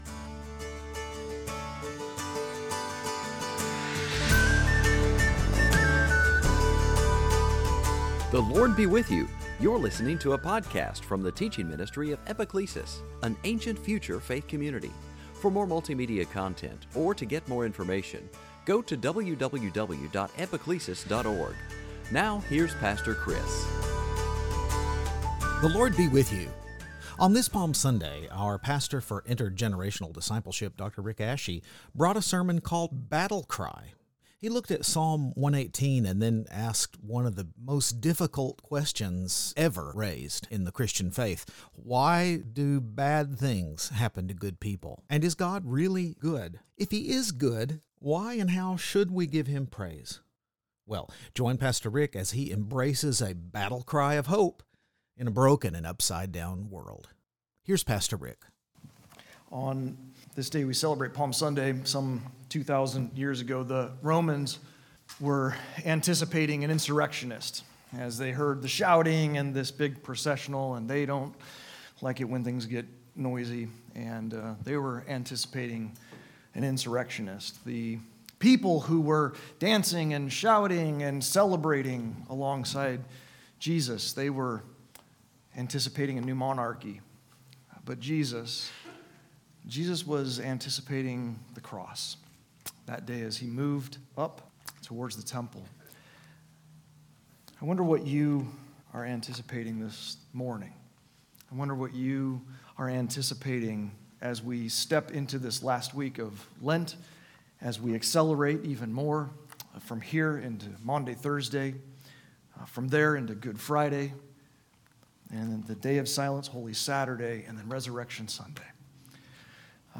2023 Sunday Teaching evil goodness of God hesed hope kaleidoscope of love lament Psalm 118 Lent